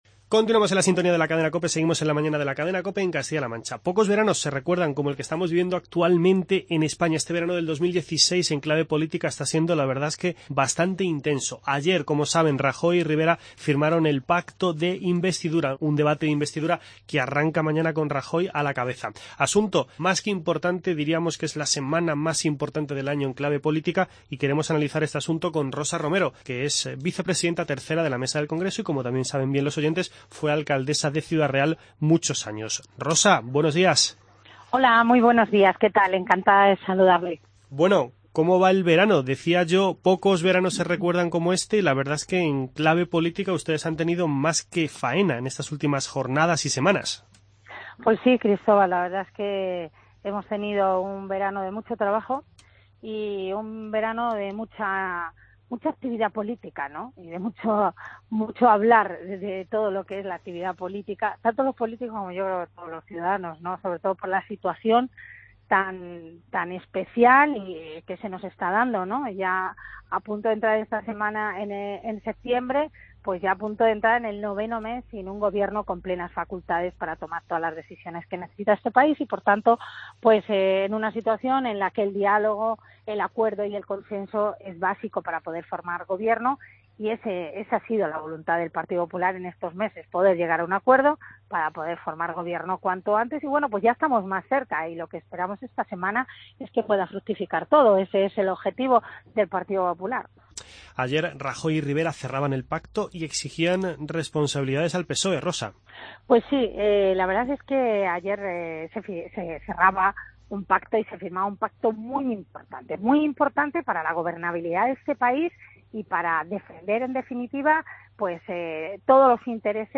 Entrevista con Rosa Romero en "La Mañana"